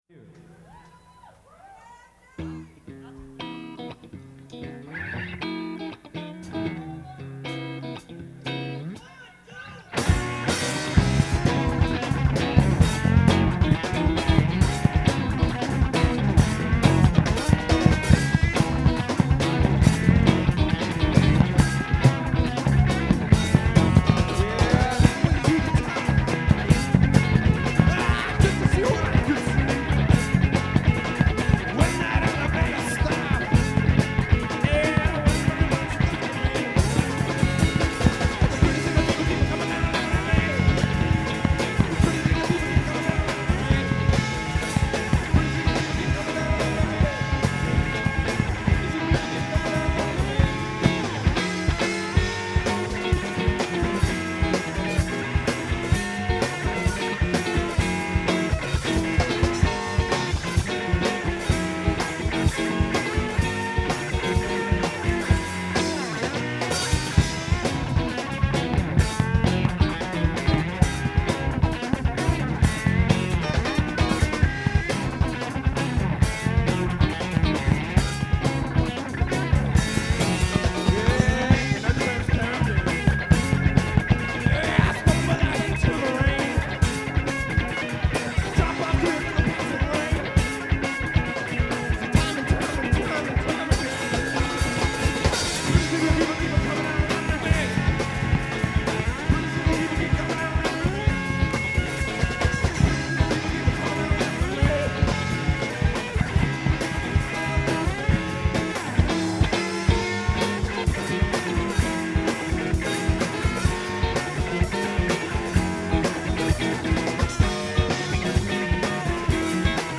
Bass
Lead Guitar, Vocals
Drums
Live at the Underground (2/9/96) [Entire Show]